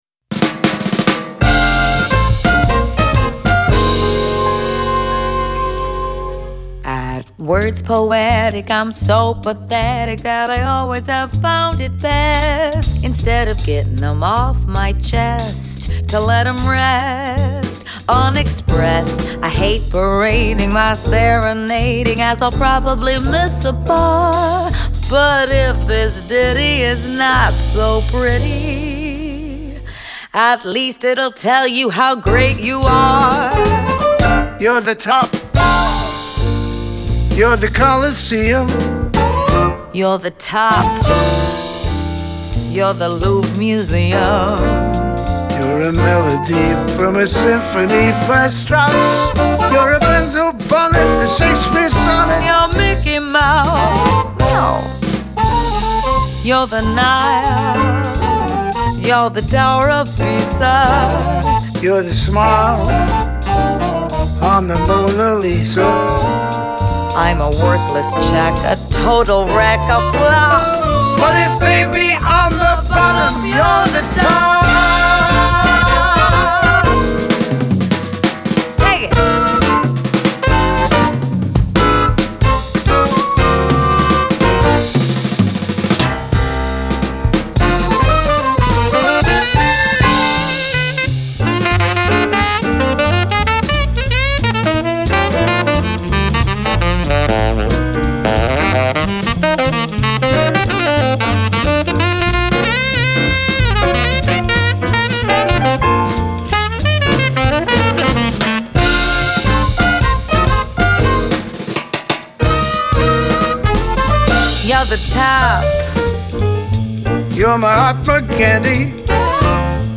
Genre : Vocal Jazz